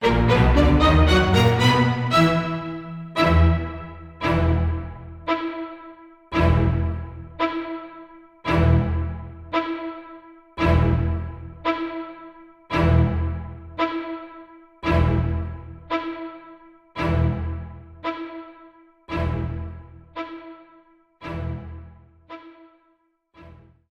Trimmed and fadeout